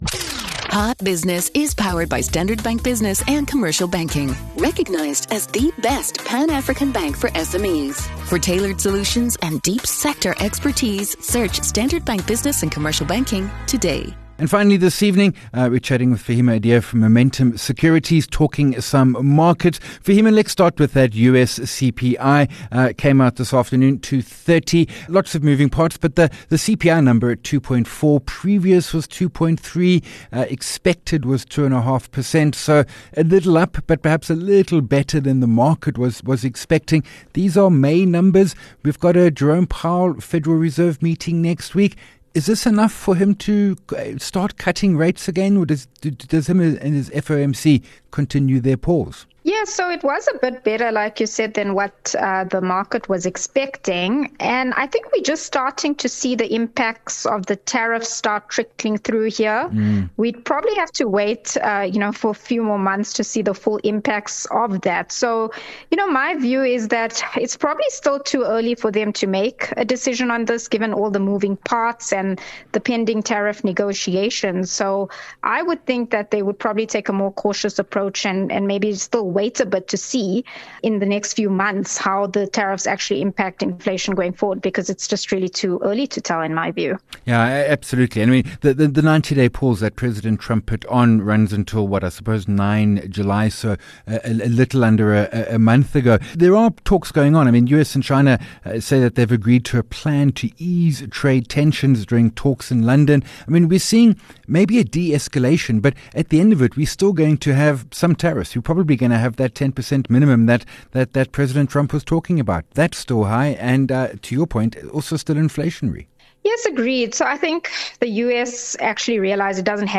HOT Business with Jeremy Maggs, powered by Standard Bank 11 Jun Hot Business Interview